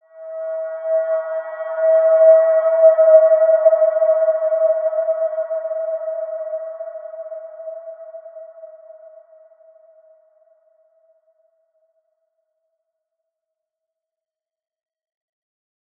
Large-Space-E5-p.wav